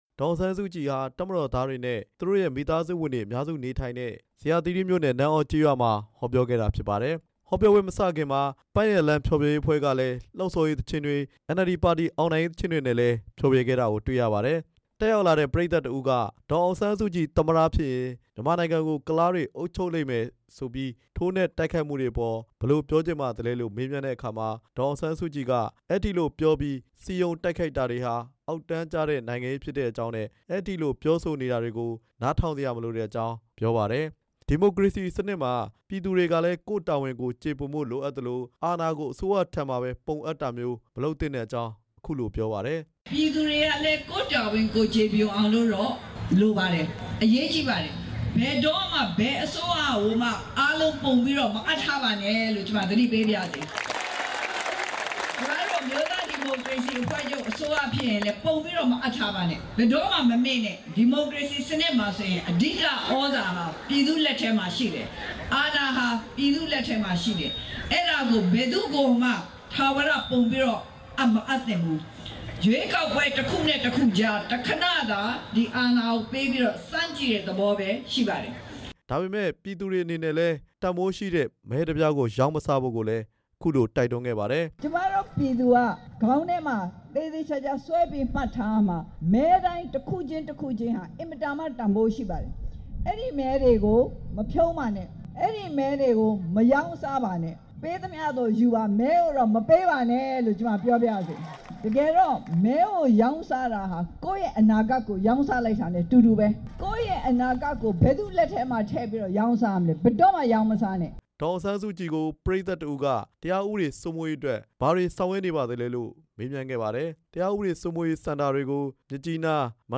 ဒီကနေ့ နေပြည်တော် ဇေယျာသီရိမြို့နယ် နန်းသြကျေးရွာမှာ ရွေးကောက်ပွဲ အသိပညာပေး ဟောပြောပွဲမှာ လူငယ်တစ်ဦးက မေးမြန်းတာကို ဒေါ်အောင်ဆန်း စုကြည်က ဖြေကြားခဲ့တာ ဖြစ်ပါတယ်။
နန်းသြကျေးရွာ ဟောပြောပွဲကို ဒေသခံ တစ်သောင်းနီးပါးလောက် တက်ရောက်ကြပါတယ်။